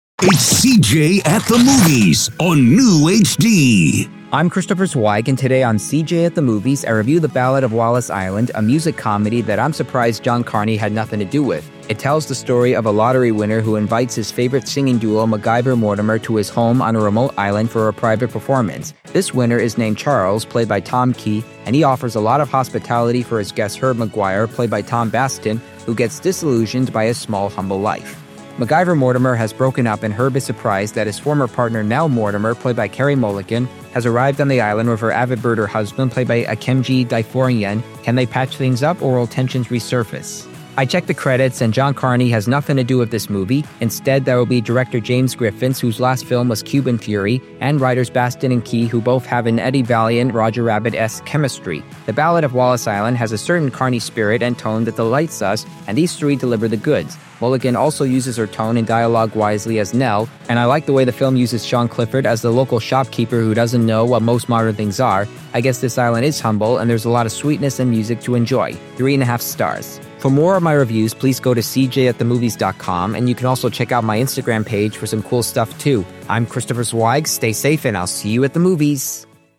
I’m part of a radio station known as NEWHD Radio, which not only hires people on the Autism spectrum, but also has me doing podcast movie reviews for them.